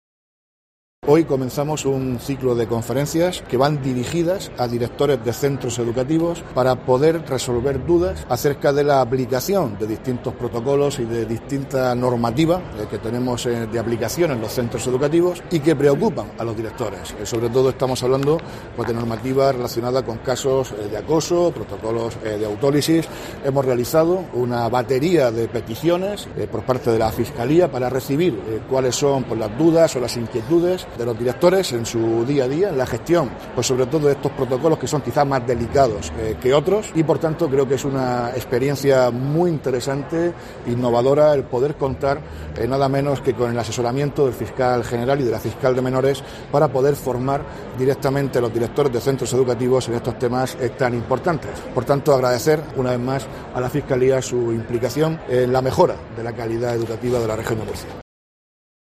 Víctor Marín, consejero de Educación